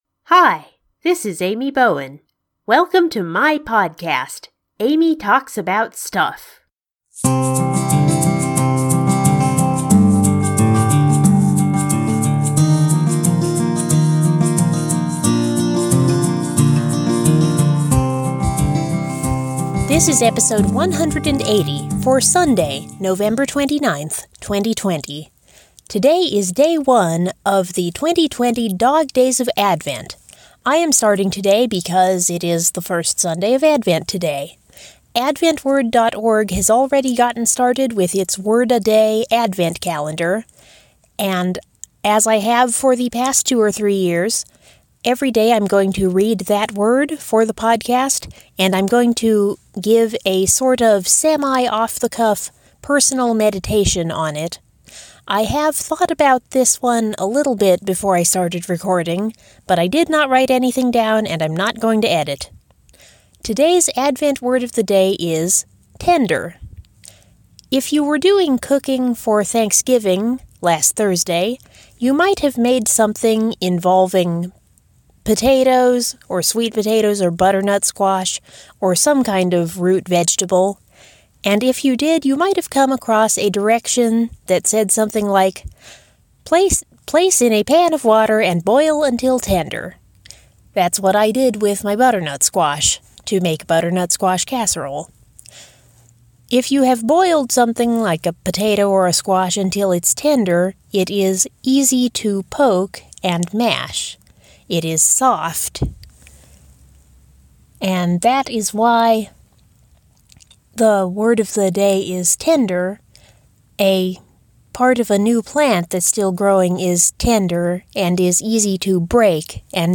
As always, I will provide a personal meditation each day on that day’s Advent Word.